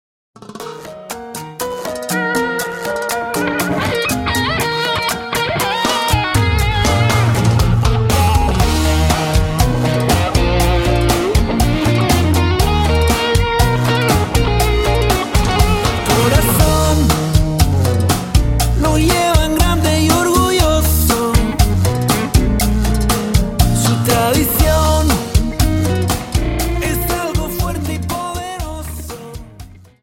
Dance: Cha Cha 30